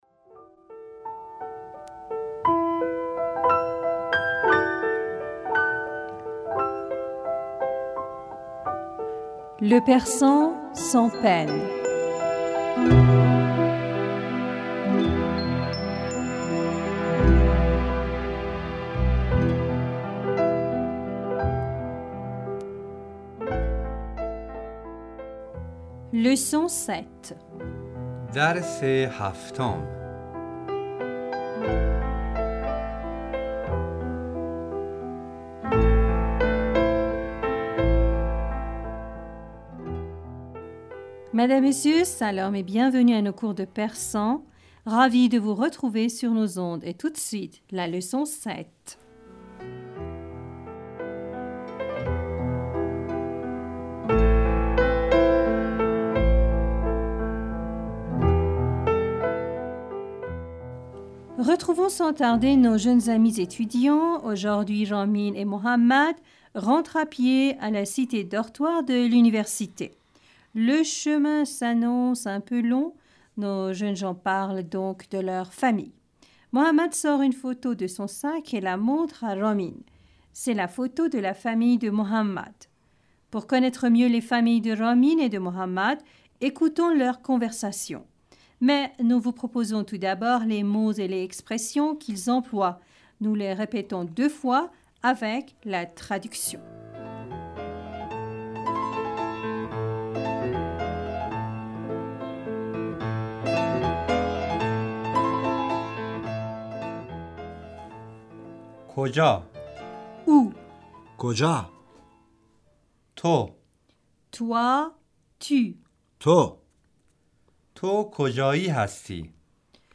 Madame, Monsieur Salam et bienvenue à nos cours de persan.
Nous répétons, chaque phrase, deux fois, avec la traduction.